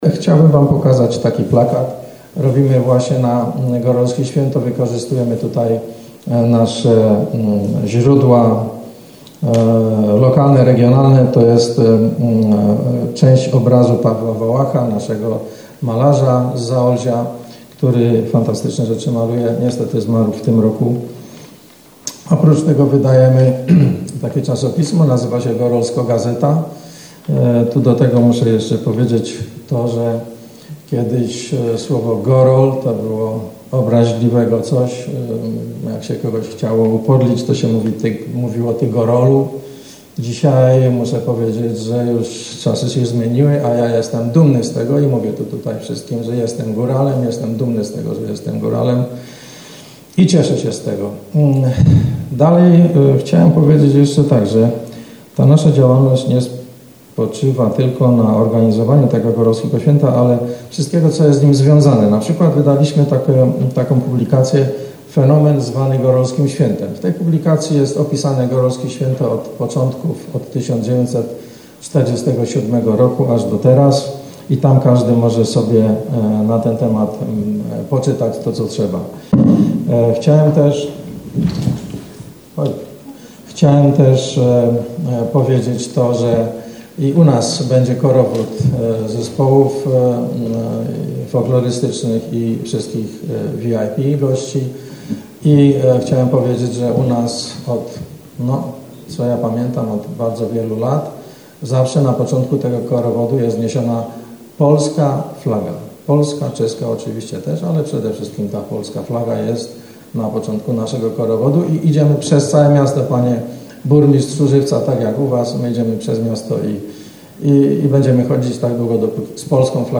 Reprezentanci Wisły, Szczyrku, Żywca, Oświęcimia, Makowa Podhalańskiego, Istebnej, Ujsół i Jabłonkowa na Zaolziu wystąpili dzisiaj podczas konferencji prasowej w Miejskiej Bibliotece Publicznej w Wiśle.